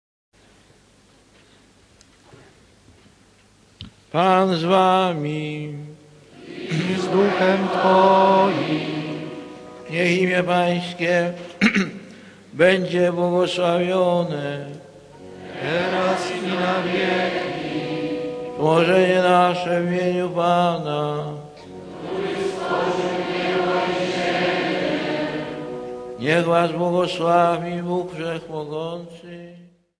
Nagranie zrealizowano na żywo w dniu 16.06.1999r w Wasowicach.
Live recording was performed on 06/16/1999 in Wadowice.